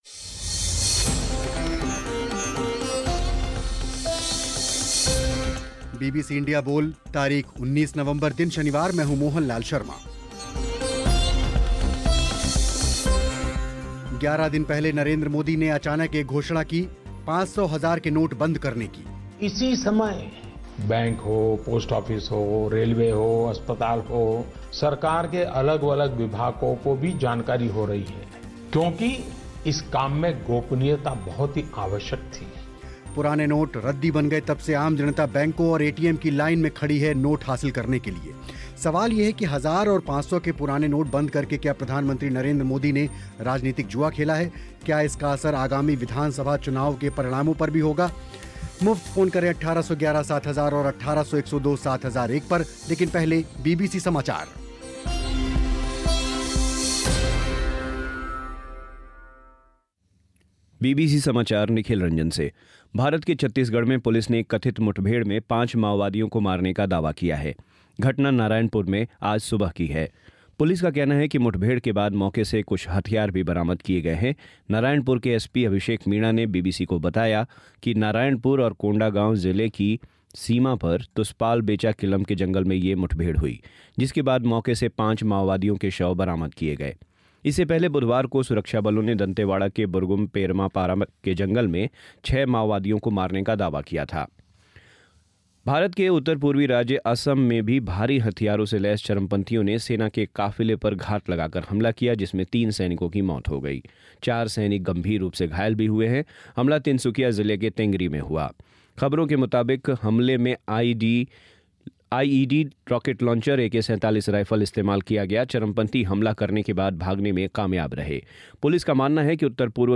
इसी पर हुई चर्चा